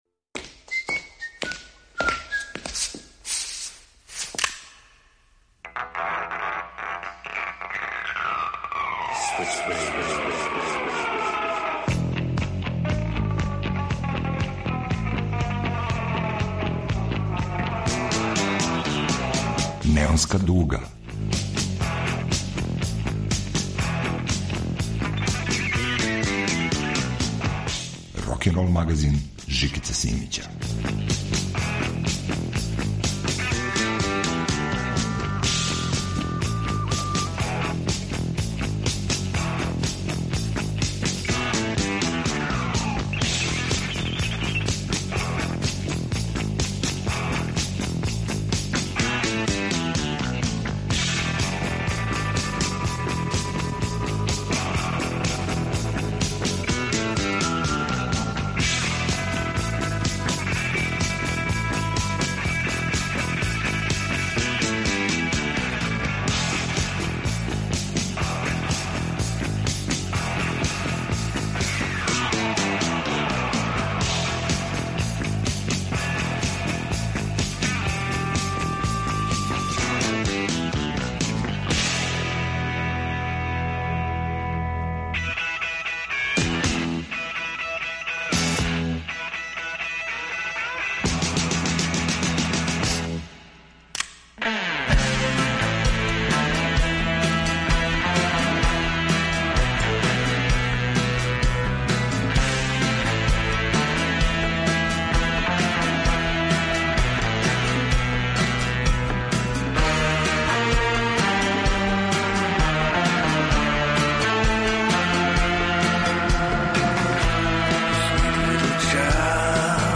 Неонска дуга ове недеље у потрази је за новим рок звуком.
Вратоломни сурф кроз време и жанрове.